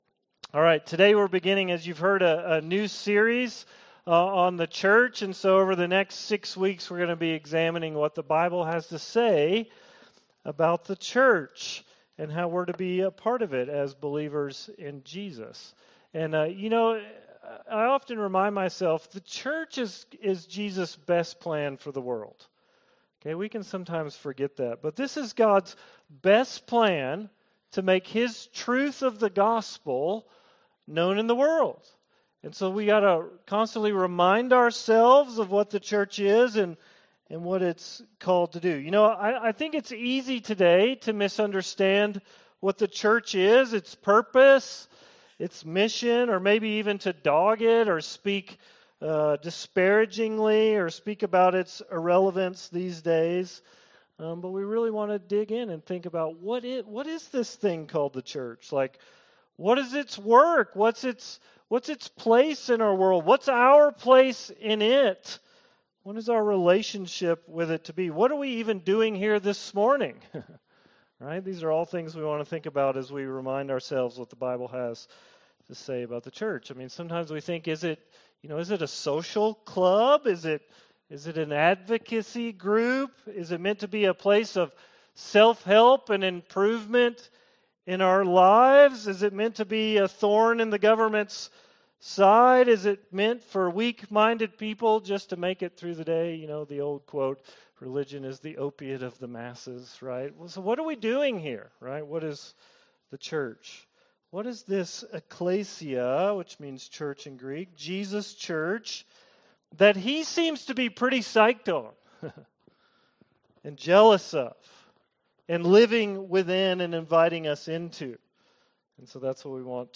Worship Listen Sermon This Sunday we will begin a new series on the Church.